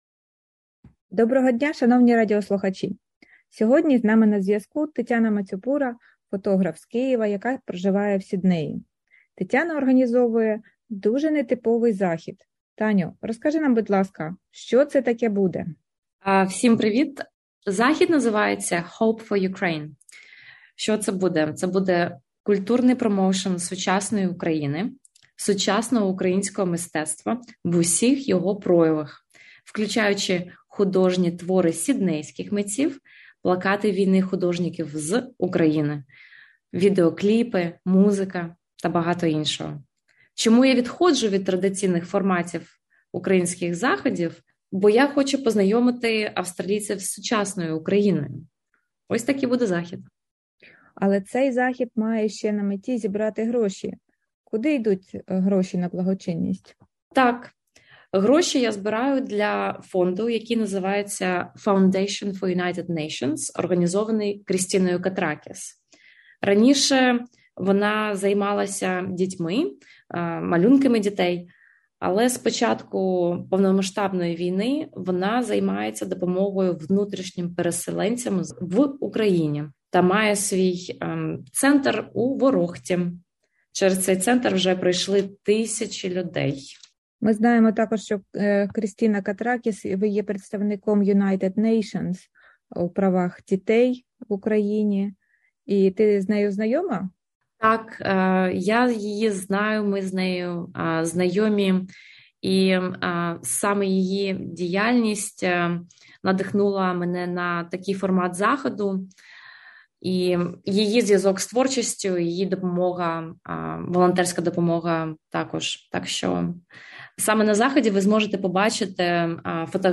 інтерв'ю